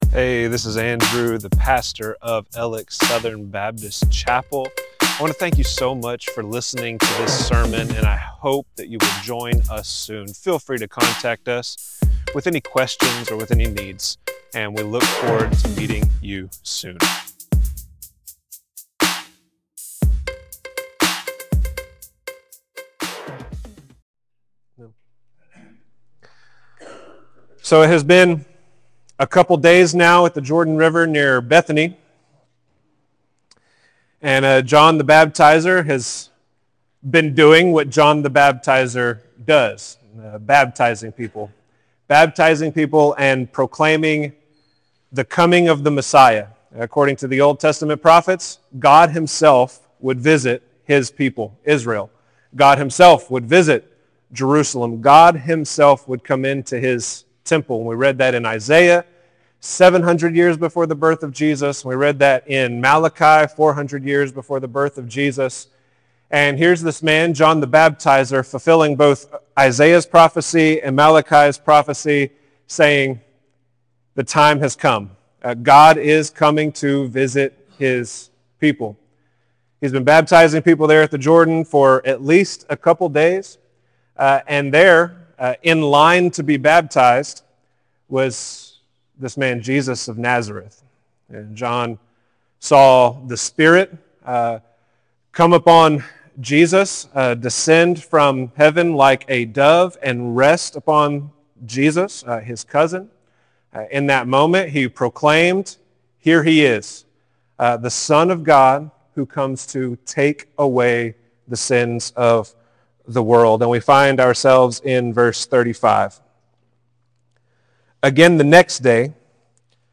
Alex Southern Baptist Chapel Sermons